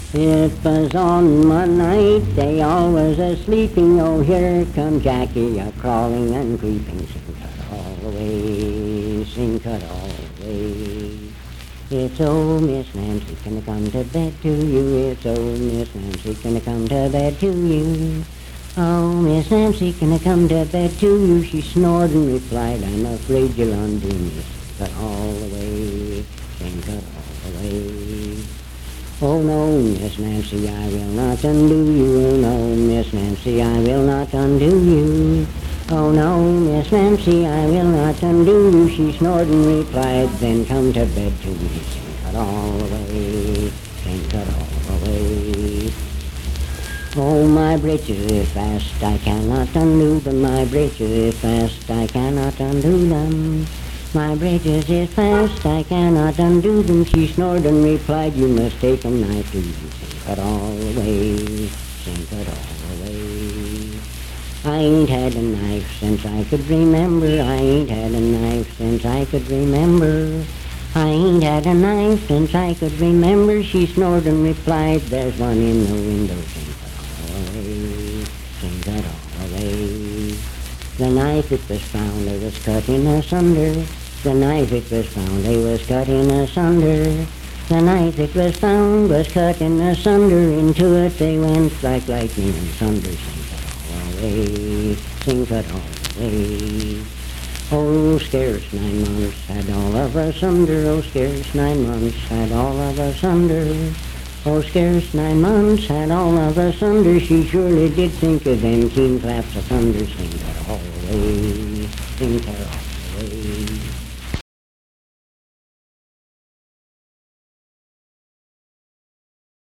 Unaccompanied vocal music
Performed in Sandyville, Jackson County, WV.
Bawdy Songs
Voice (sung)